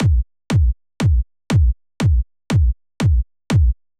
31 Kick.wav